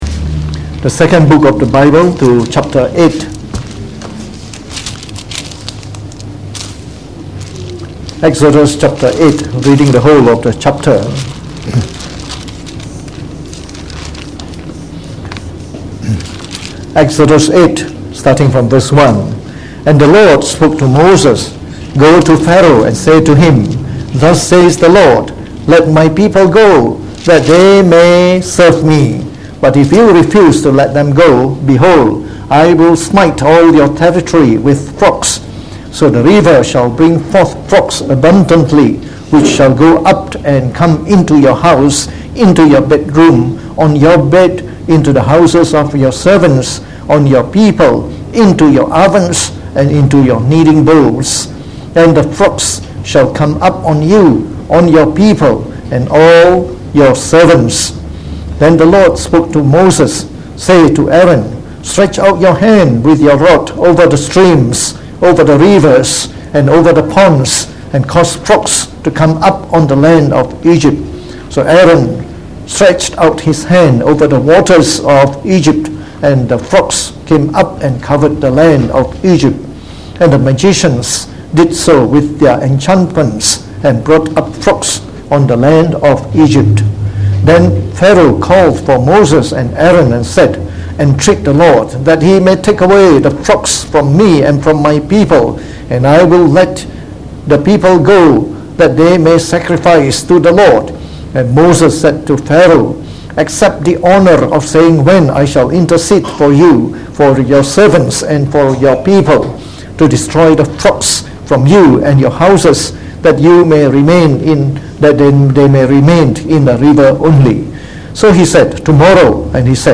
Sermon on the book of Exodus delivered in the Morning Service